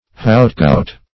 Meaning of hautgout. hautgout synonyms, pronunciation, spelling and more from Free Dictionary.
Search Result for " hautgout" : The Collaborative International Dictionary of English v.0.48: Hautgout \Haut`go[^u]t"\ (h[=o]`g[=oo]"), n. [F.]